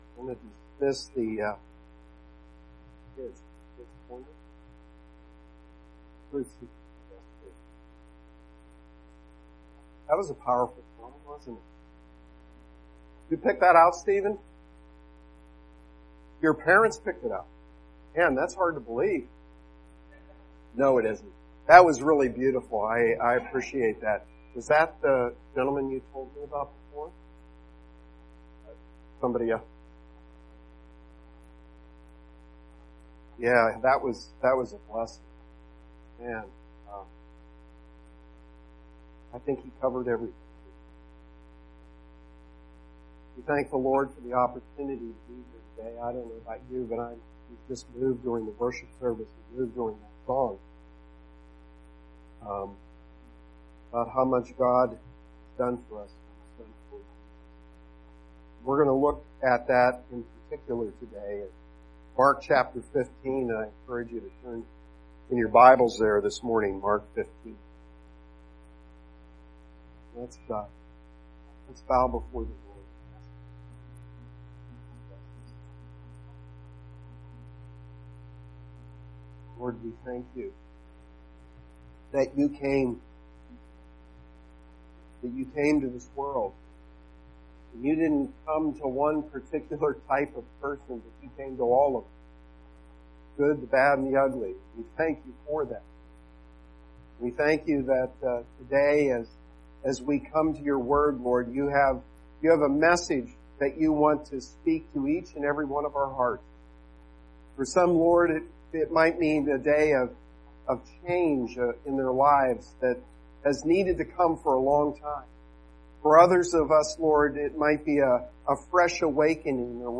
Sermon7-29-18.mp3